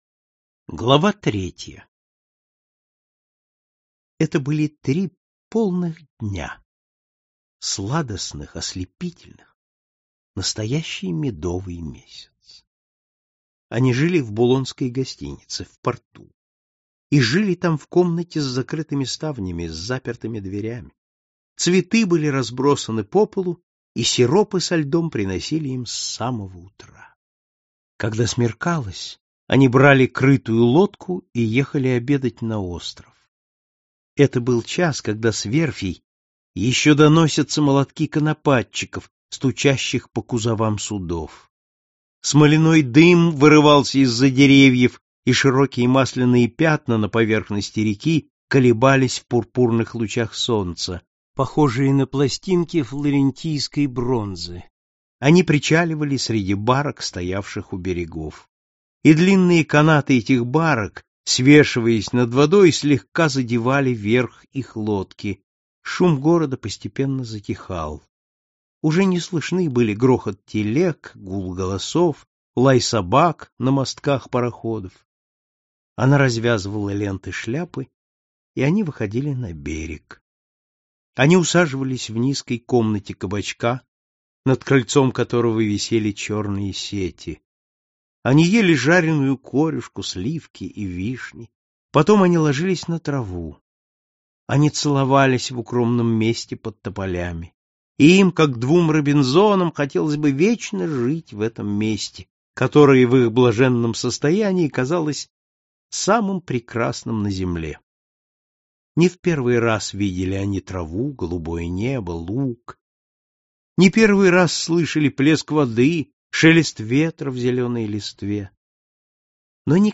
Аудиокнига Госпожа Бовари | Библиотека аудиокниг